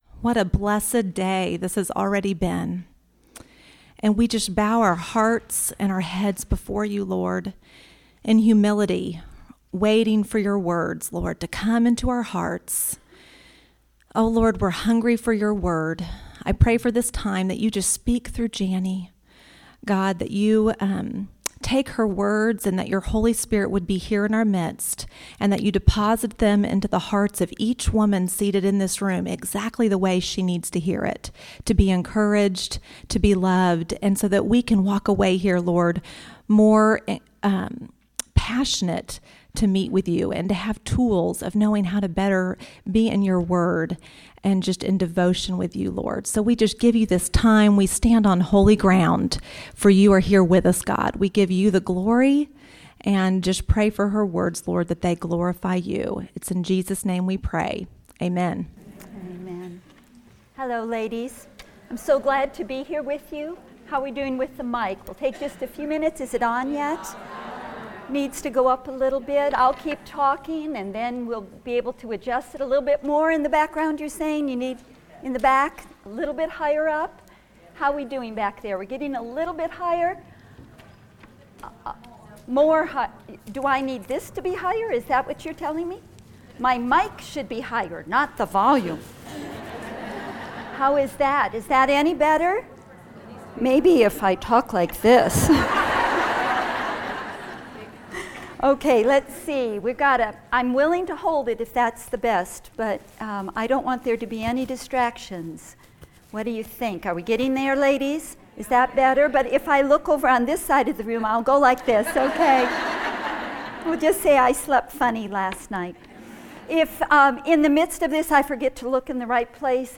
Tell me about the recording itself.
Your Time in God's Treasures—Cultivating a Meaningful Devotional Life | True Woman '14 | Events | Revive Our Hearts